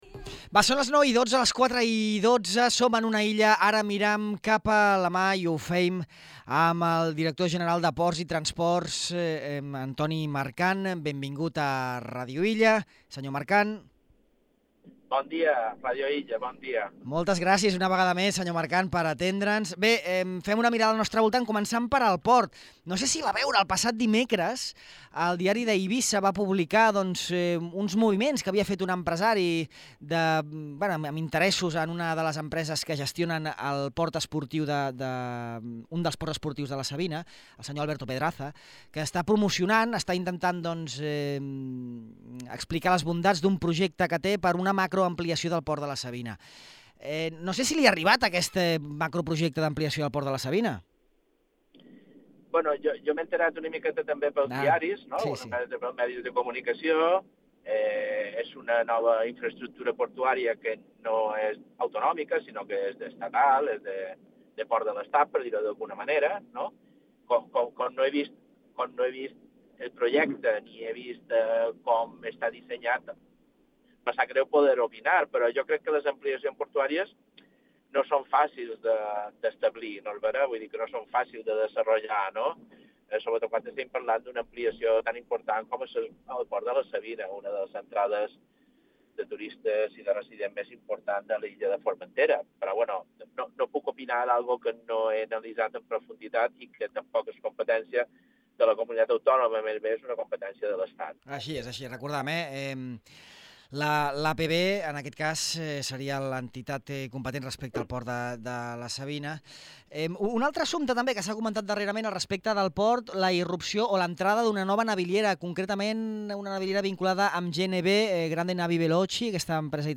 El projecte de decret de Transport Marítim de les Balears es troba encallat des de l’any passat en la darrera fase de tramitació, a l’espera del vistiplau de la Comissió Europea, que ha requerit més documentació al Govern autonòmic, segons ha explicat el director general de Ports i Transport Marítim, Antoni Mercant en aquest entrevista a Ràdio Illa.